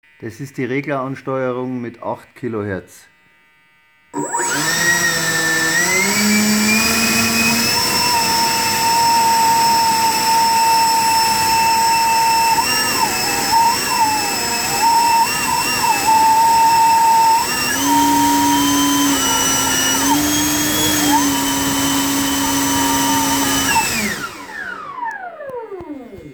Kurz vor Halbgasdrehzahl kommt ein "leicht pfeifender Ton" zu dem ansonsten normalen Laufgeräusch hinzu.
Z. B. bei 8KHz deutlich lauter als bei 16KHz..
Die MP3 Files sind von einem Impeller.
8KHzAnsteuerungTiming5.wav